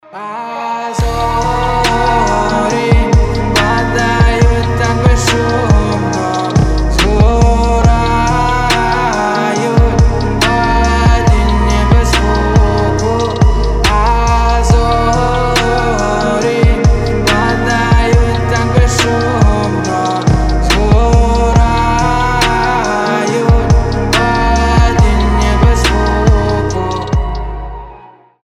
красивые
спокойные
медленные
расслабляющие
плавные